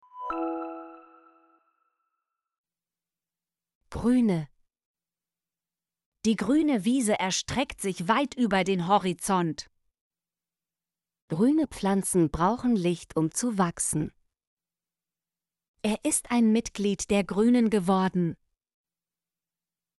grüne - Example Sentences & Pronunciation, German Frequency List